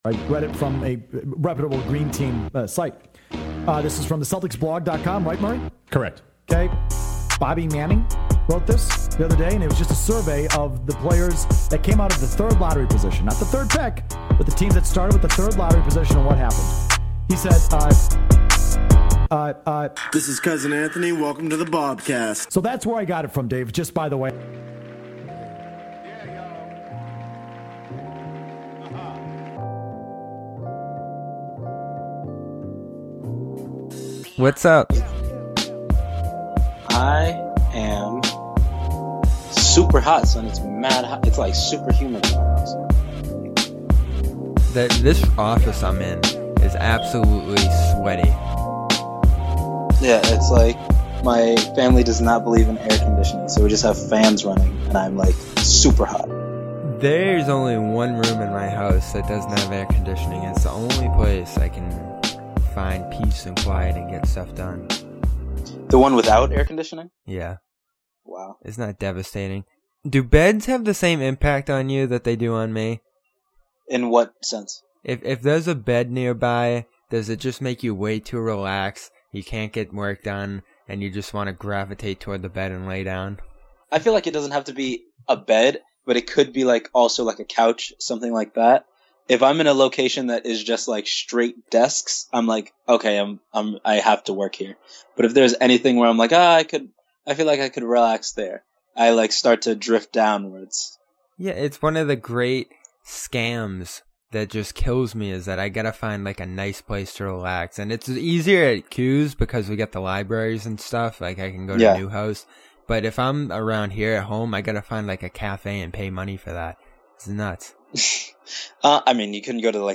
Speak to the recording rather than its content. We broadcasted live (60 min late thanks to me) after the Russell Westbrook stunner.